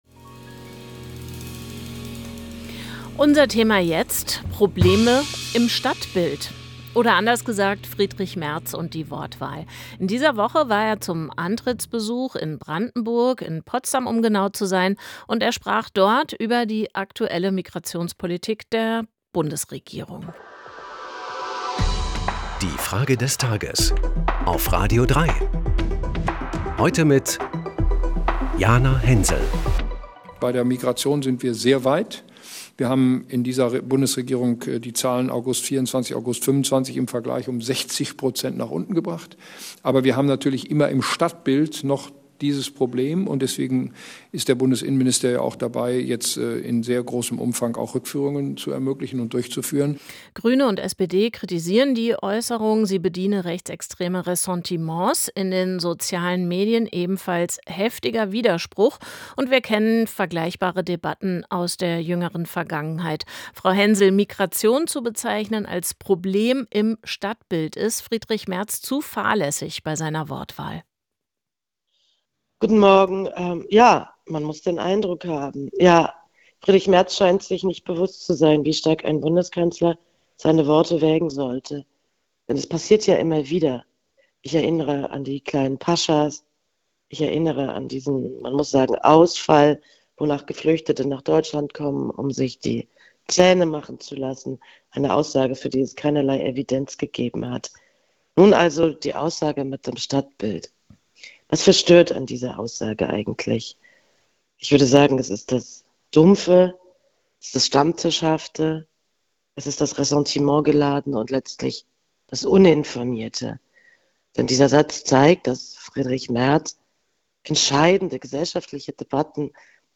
Autorin und Journalistin Jana Hensel.